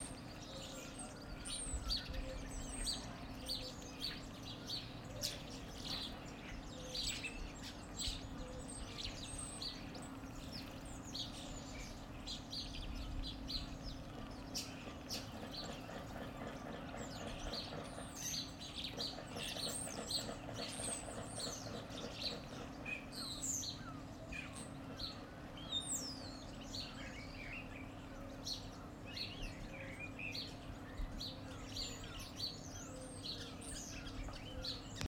Starlings, sparrows (and builders and collared doves)
Birdsong